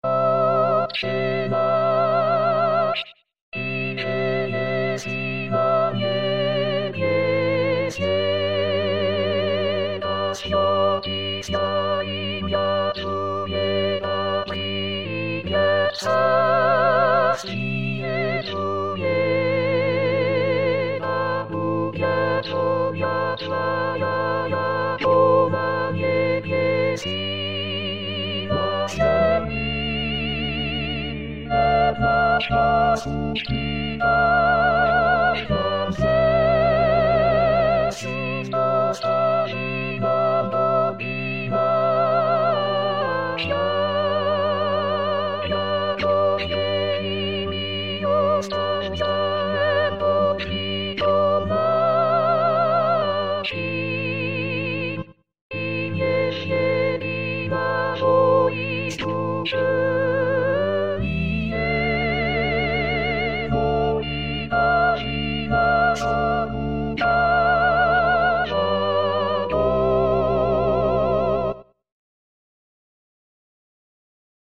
Soprano.mp3